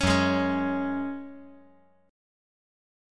F#sus2 Chord
Listen to F#sus2 strummed